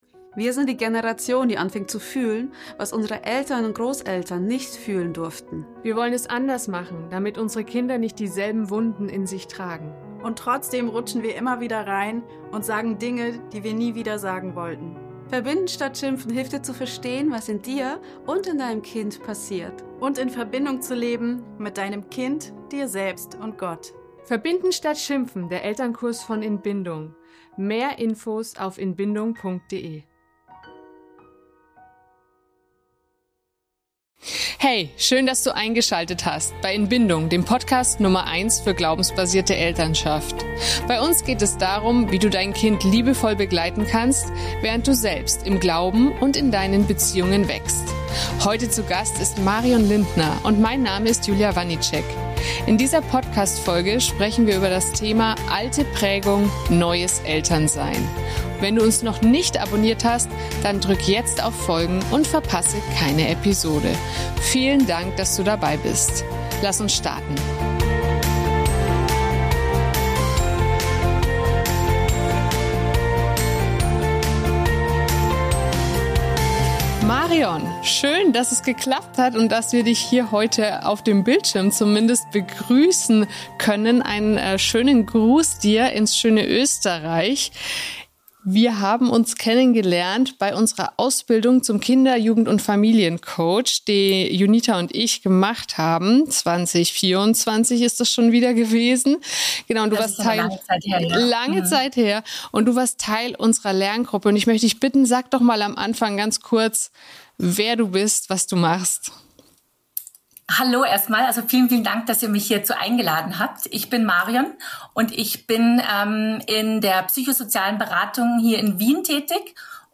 In dieser Folge sprechen wir mit Kinder-, Jugend- und Familiencoach